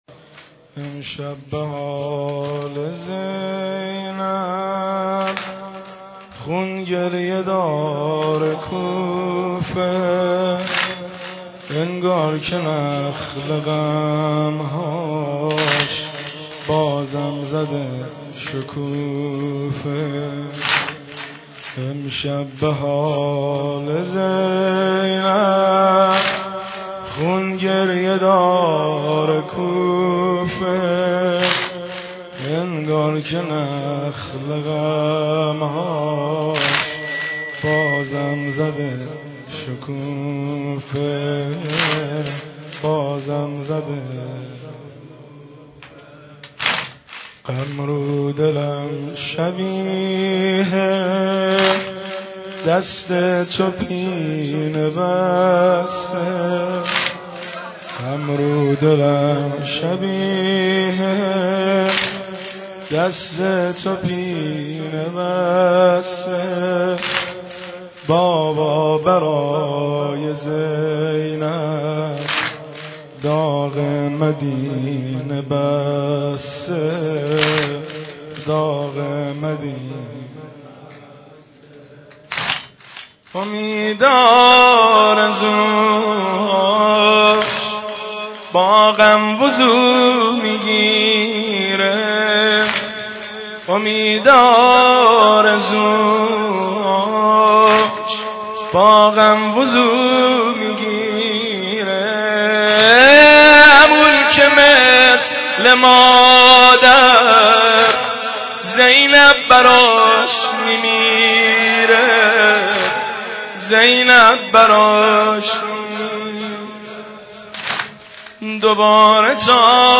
مداحی
نوحه